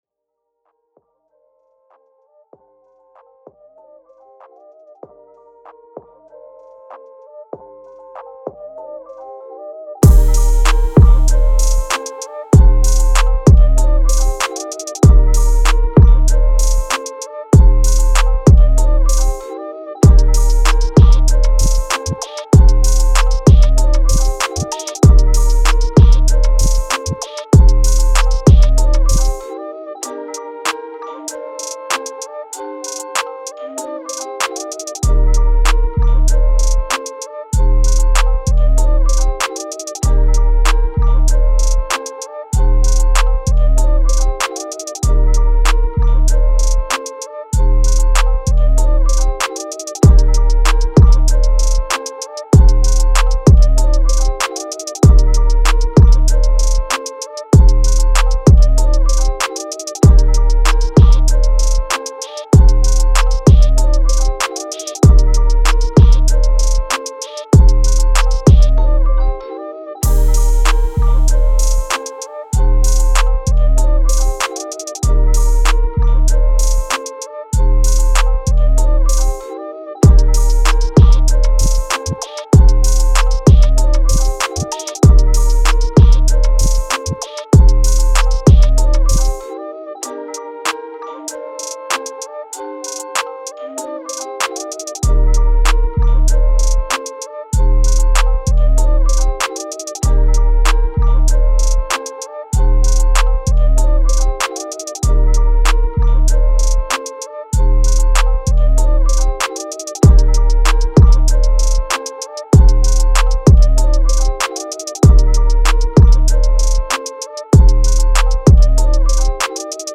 Rap- Beats